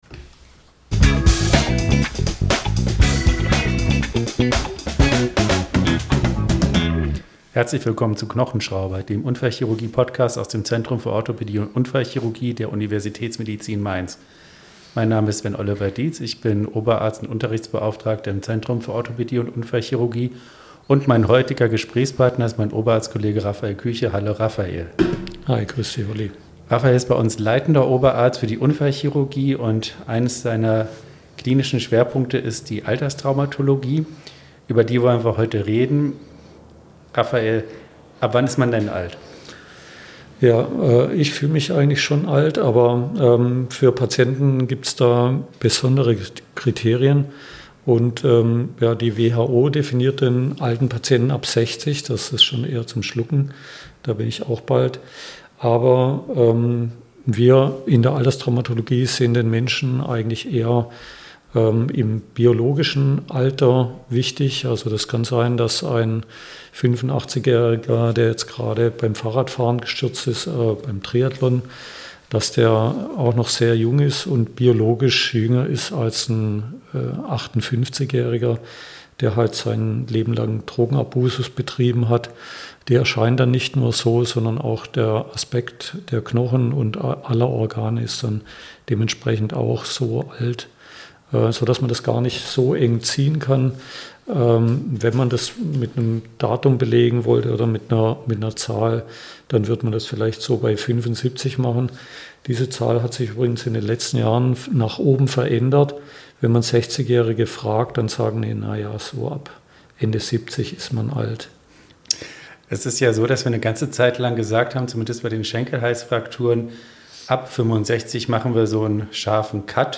Zusammen mit seinen Interviewgästen nimmt er Euch mit in den klinischen Alltag am ZOU, bespricht mit ihnen praxisnah relevante Krankheitsbilder, diagnostische und therapeutische Prinzipien sowie typische Fallbeispiele – fundiert, verständlich und auf Augenhöhe.